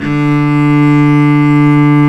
Index of /90_sSampleCDs/Roland - String Master Series/STR_Cb Bowed/STR_Cb3 Arco nv
STR CELLO 04.wav